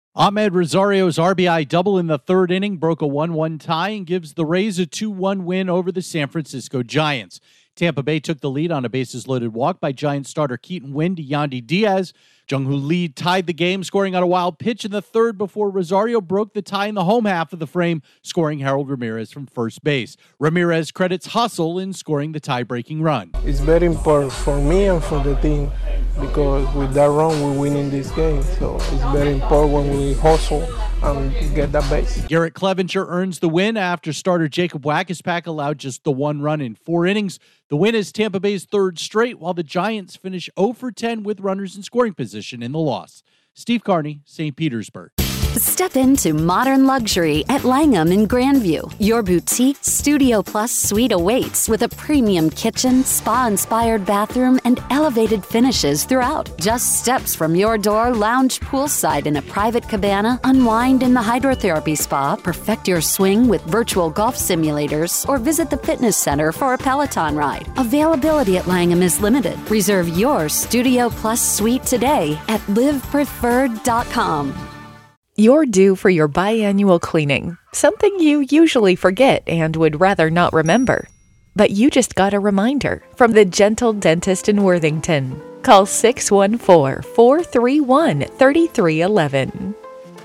The Rays use their hustle to score the tiebreaking run against the Giants. Correspondent